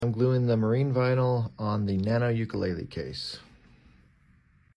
Nano Ukulele Case.